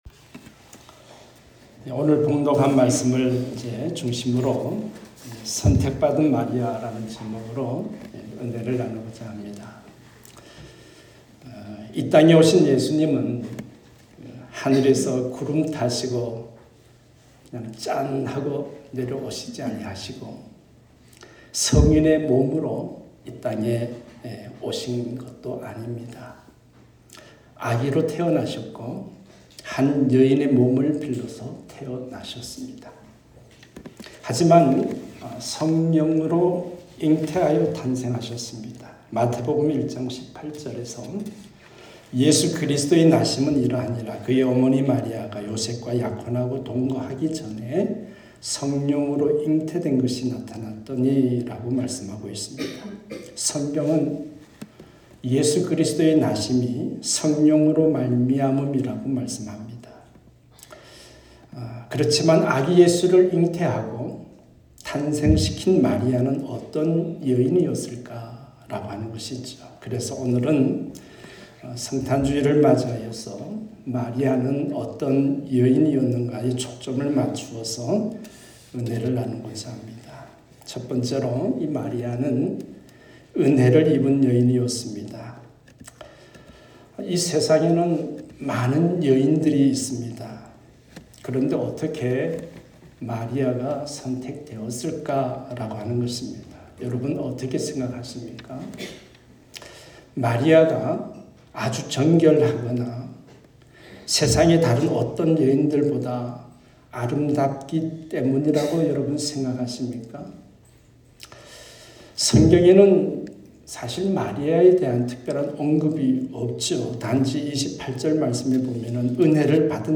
선택 받은 마리아 ( 눅1:26-38 ) 말씀